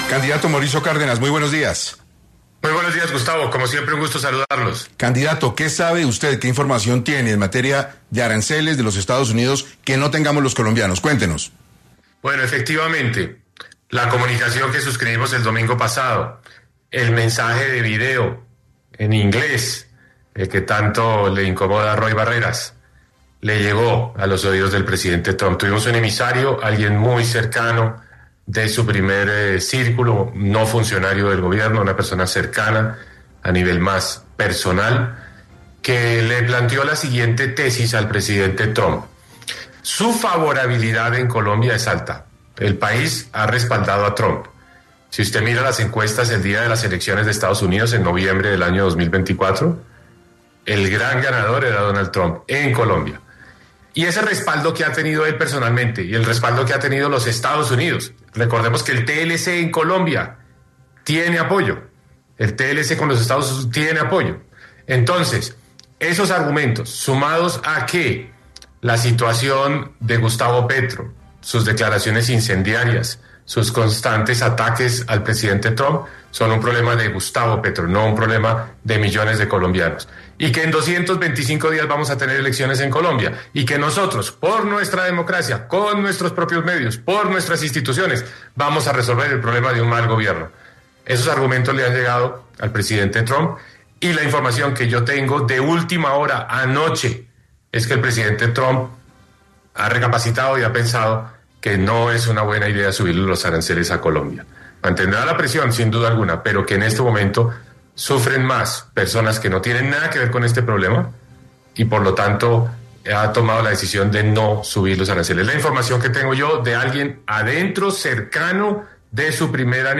El exministro y precandidato Cárdenas pasó por 6AM para abordar las implicaciones actuales en la relación bilateral entre Colombia y Estados Unidos.